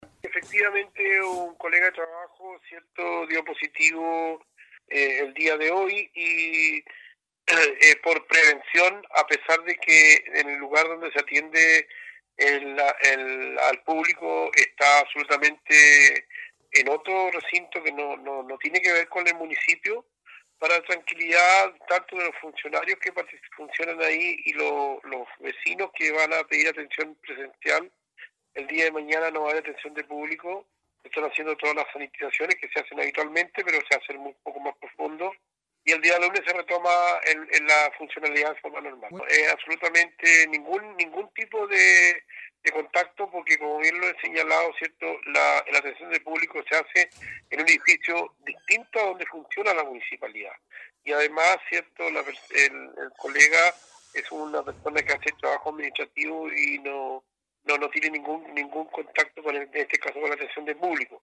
En tanto, en Dalcahue, un funcionario de la municipalidad de esa comuna, también está contagiado, teniendo alrededor de cuatro a cinco colegas de trabajo como contactos estrechos, pero que no ha afectado la atención de público, como lo declaró el alcalde Juan Hijerra.